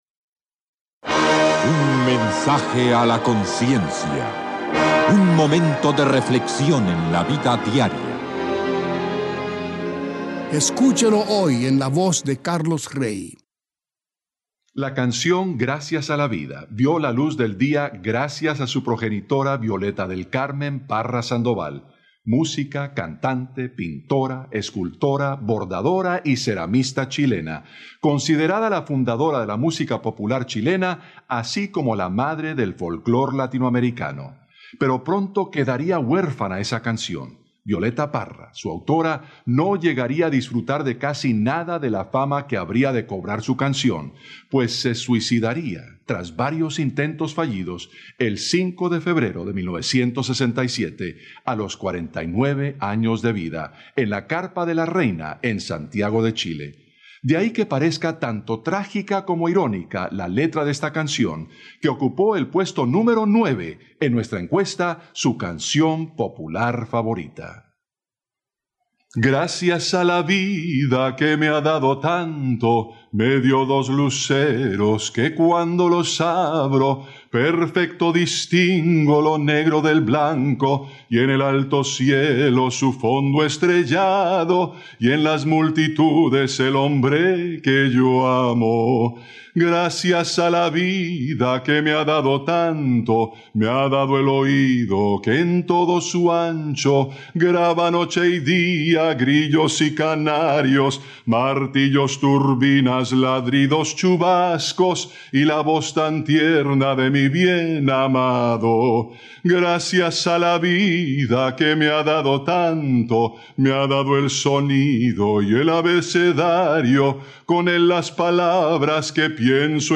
Mensajes a la Conciencia: mensajes breves a modo de par�bolas cristianas en texto, audio y video.